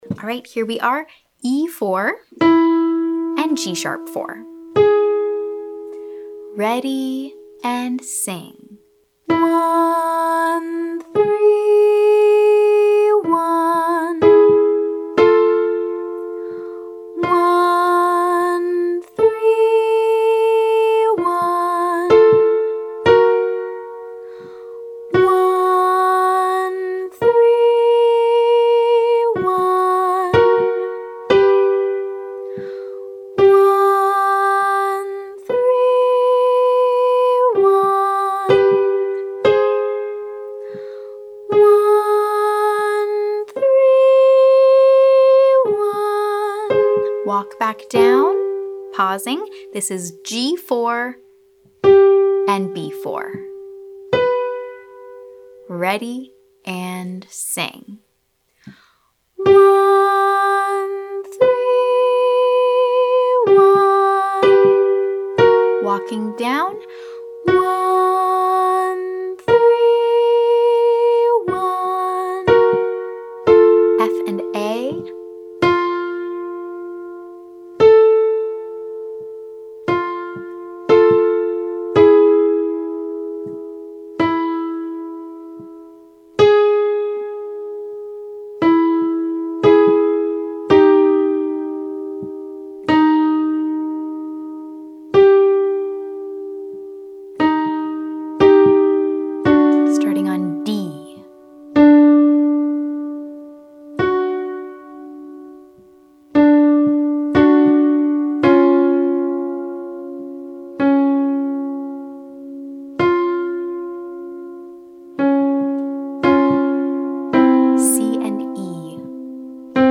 Exercise - 131: sing with piano playing individual pitches